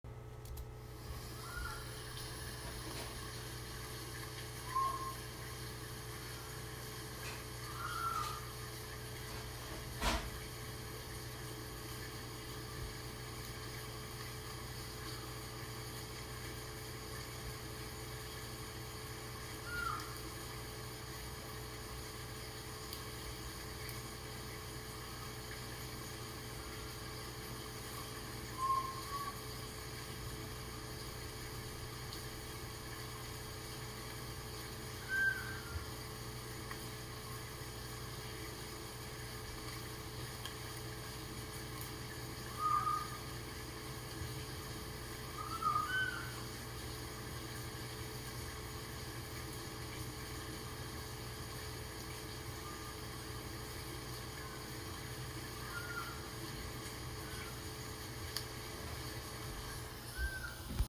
Breeding occurs during the cooler months, typically from December to March, when the males emit a soft call near slow-moving stream pools to attract females.
Ishikawa's Frog calling during mating season:
Ishikawas-Frog-Call.m4a